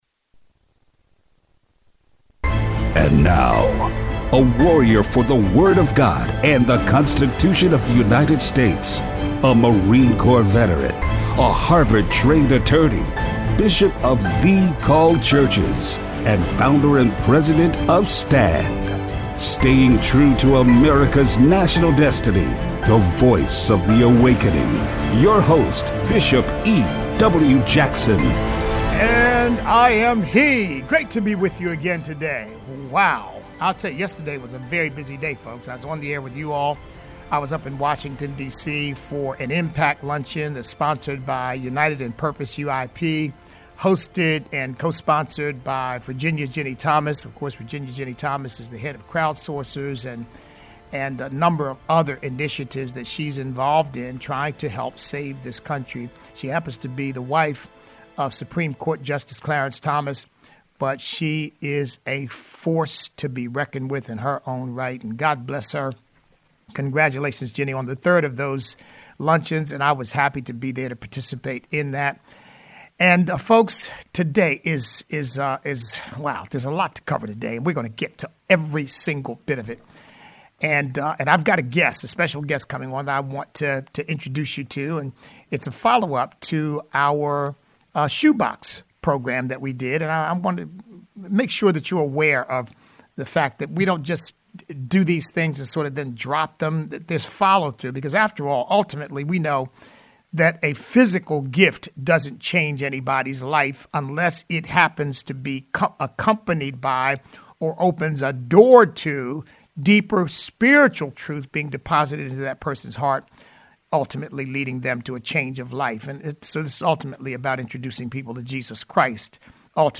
Listener call-in.